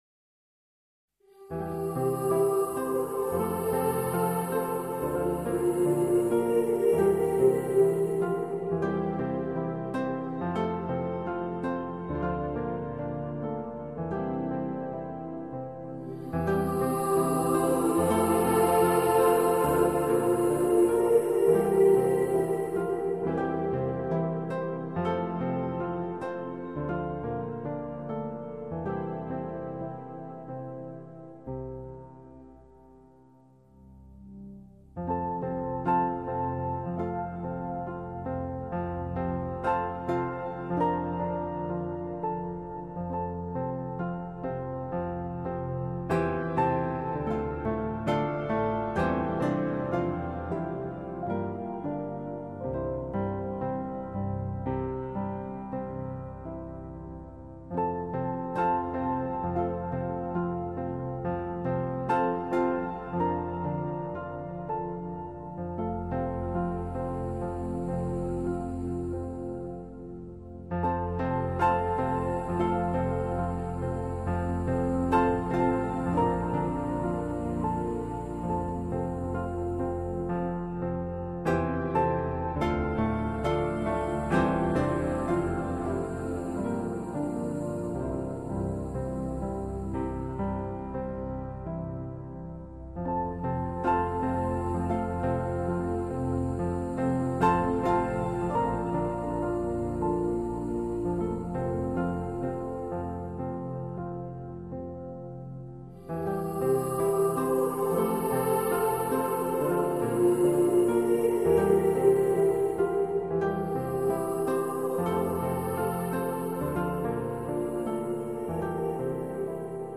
她的歌给人的是一种平静和催眠的感觉。